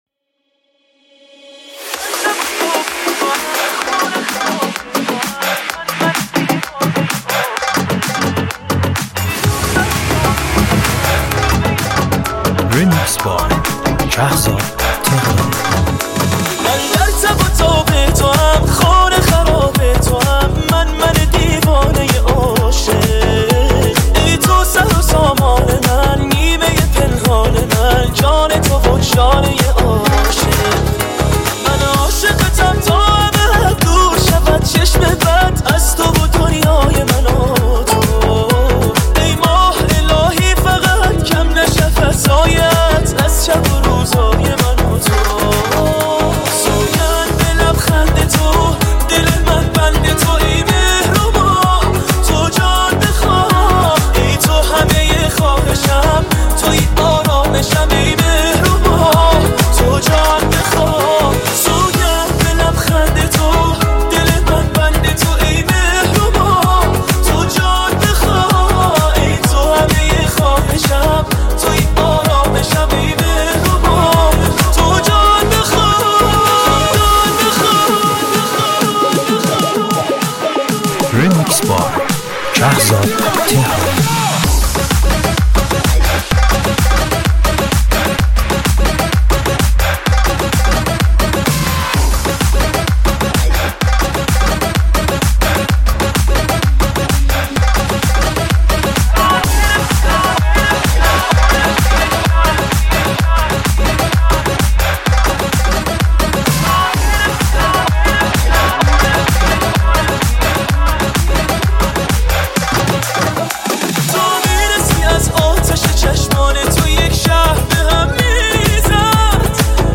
Exclusive Remix